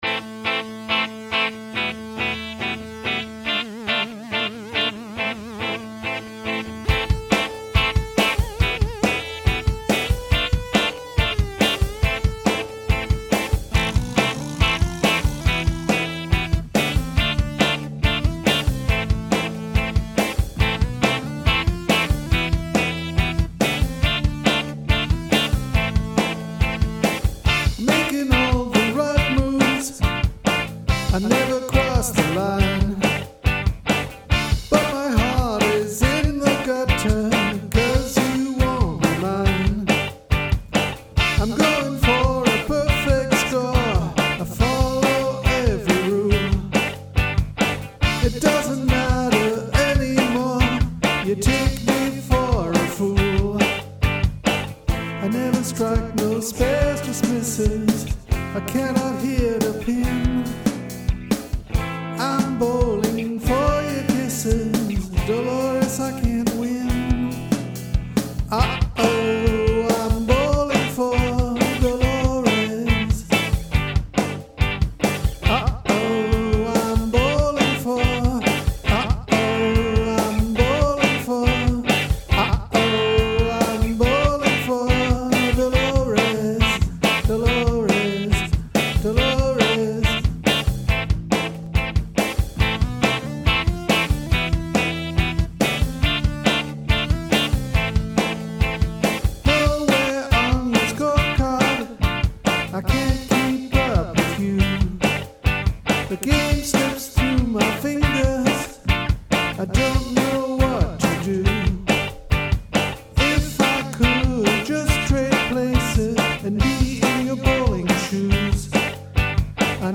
It took maybe another hour to come up with the music, and record a quick demo, which is attached in all its synthy cheesiness.